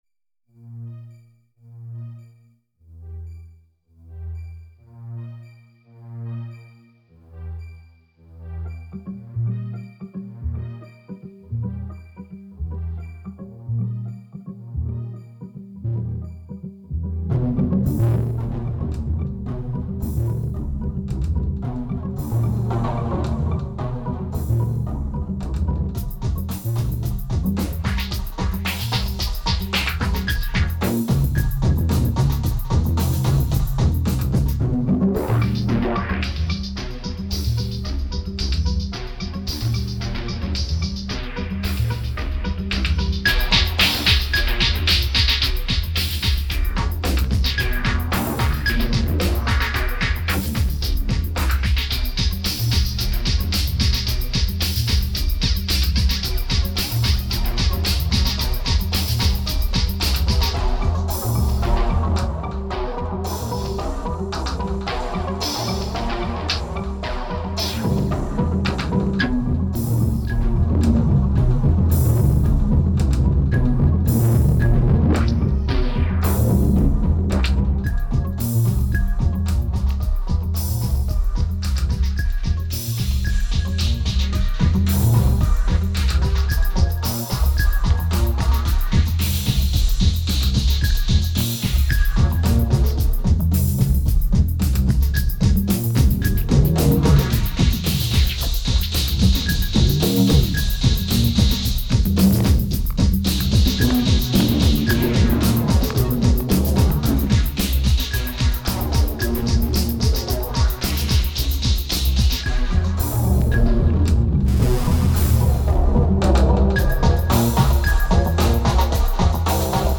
2222📈 - -26%🤔 - 111BPM🔊 - 2008-10-17📅 - -319🌟